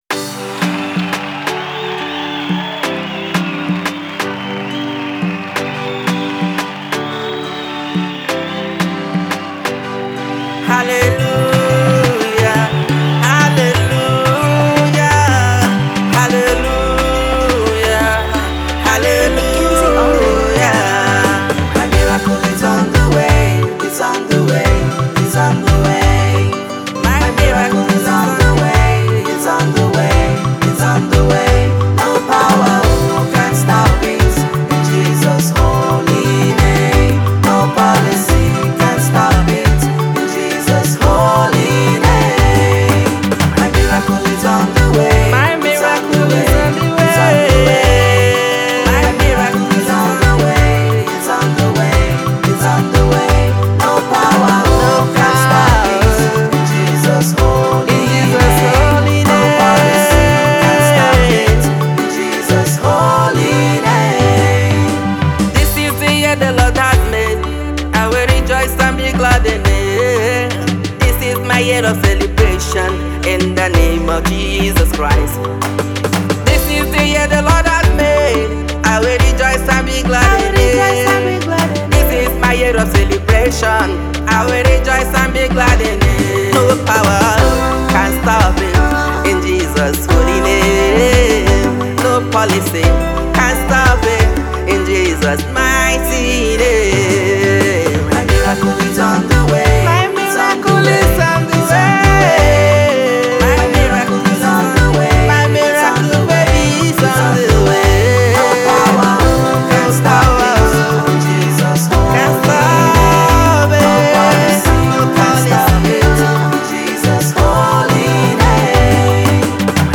Abuja Based Prolific Gospel singer